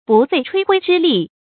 bù fèi chuī huī zhī lì
不费吹灰之力发音